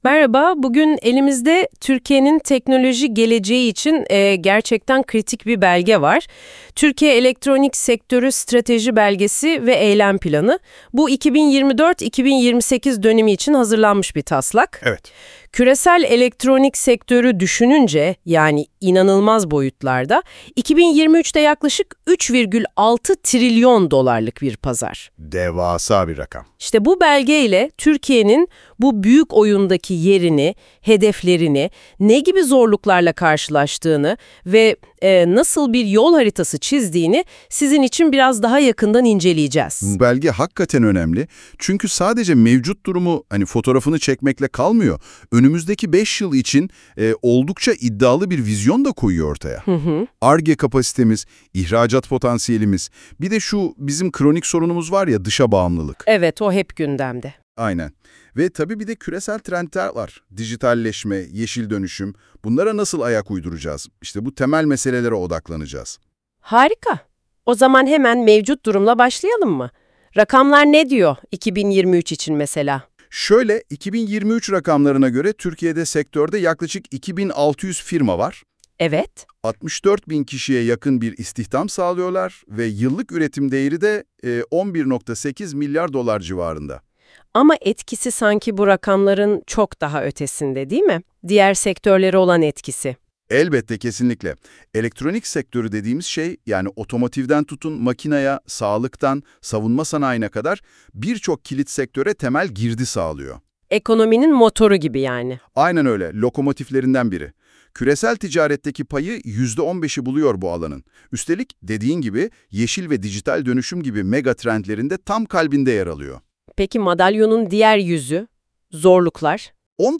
Belgenin Google NotebookLM ile hazırlanmış sohbet haline